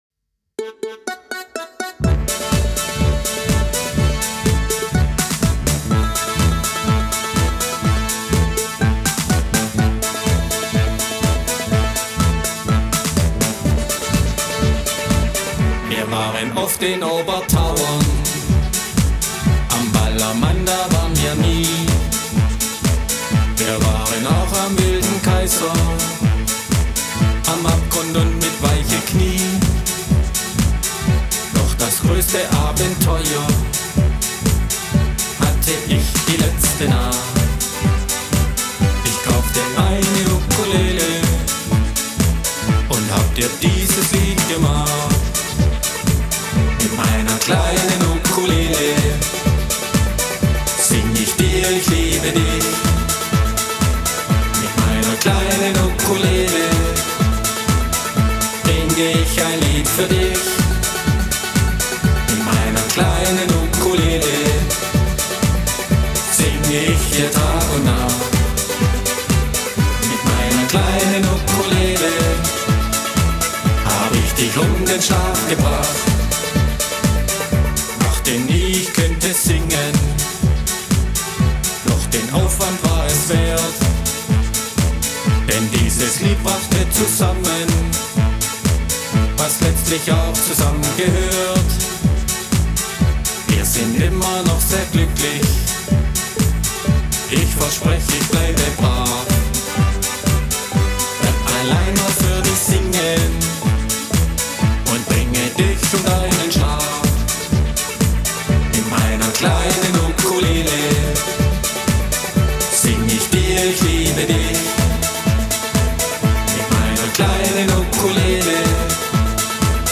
Ich habe mich mit Home-Recording und eigenen Songs beschäftigt.
Gesang
Eine Runde Disco-Fox!